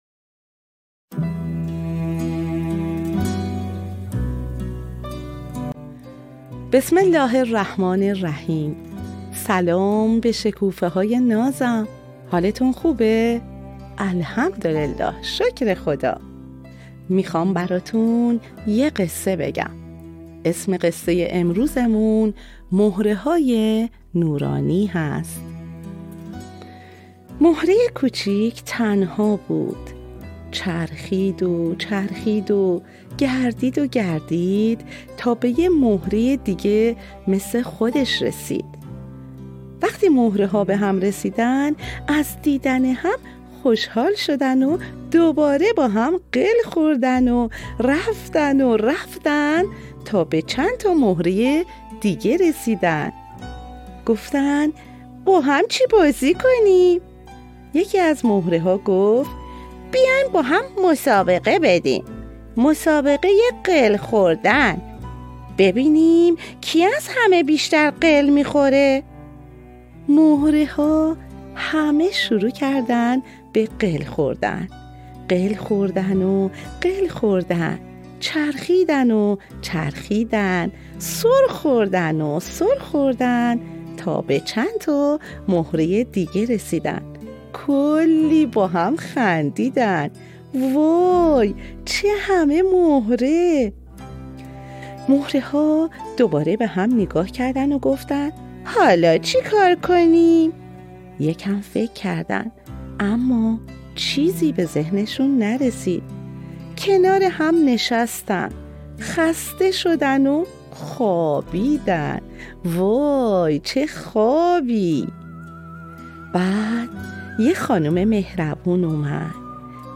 قصه صوتی - مهره های نورانی